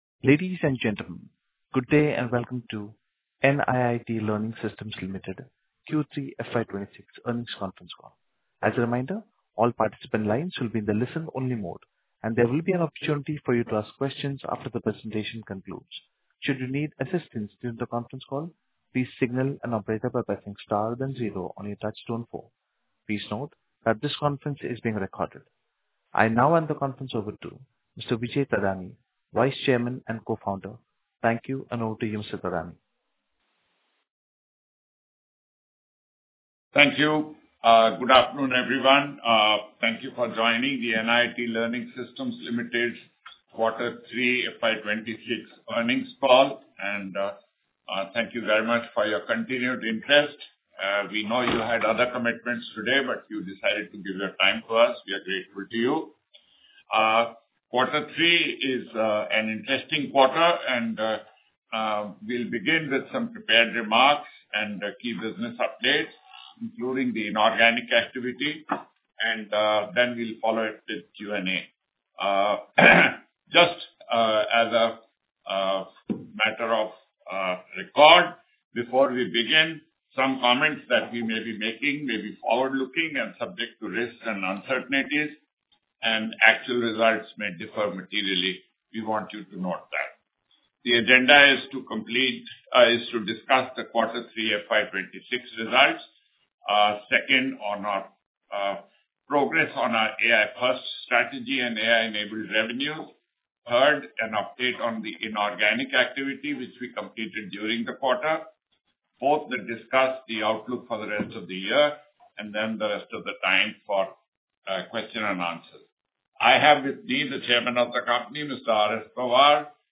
NIIT Learning Systems Provides Audio Recording of Q3FY26 Earnings Call Under Regulation 30